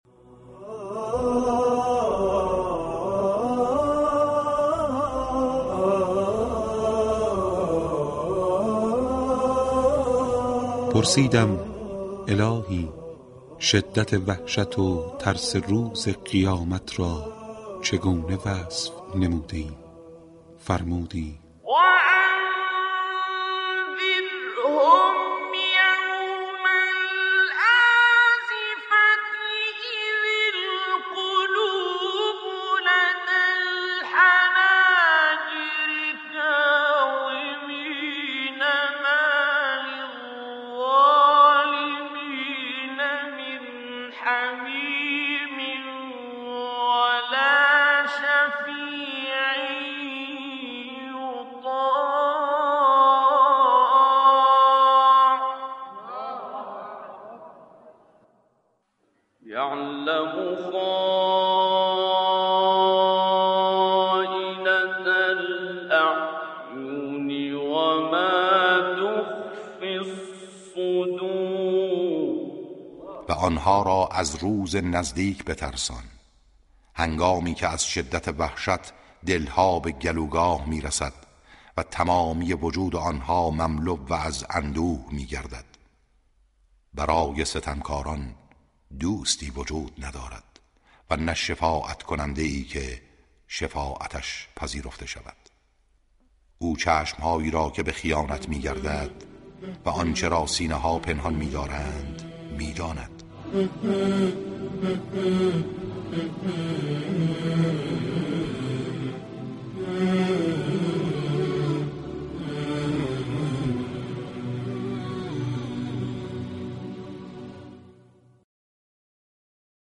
تلاوت آیات هشدار قیامت در «پرسیدم» با صدای استادعبدالباسط
برنامه «پرسیدم» با پخش تلاوت آیات 18 و 19 سوره «غافر» توسط استاد عبدالباسط محمد عبدالصمد، به هشدار قرآن درباره لحظات هول‌انگیز قیامت پرداخت.